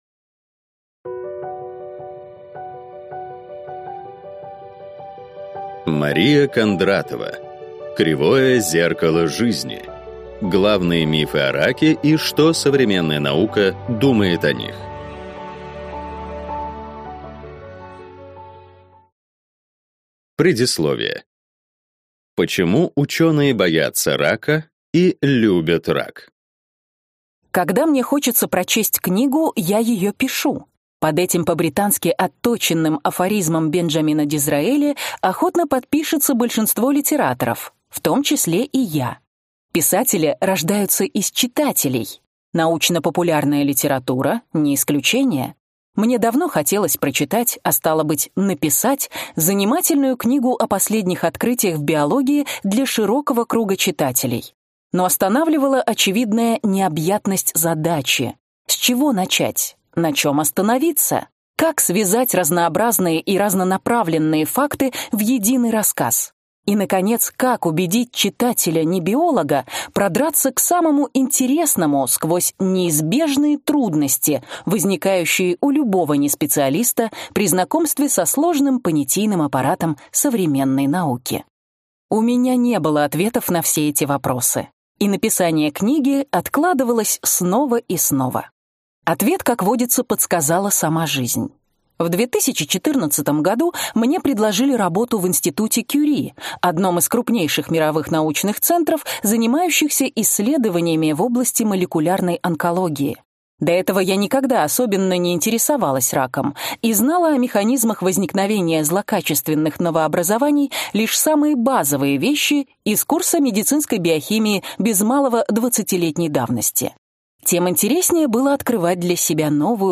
Аудиокнига Кривое зеркало жизни | Библиотека аудиокниг